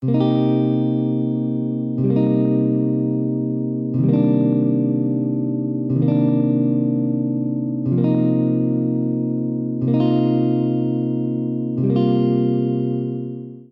Minor 7th chords and extensions
Chord Name Cmin7 Cmin7 Cmin9 Cmin9 Cmin11 Cmin11 Cmin11
minor_chords.mp3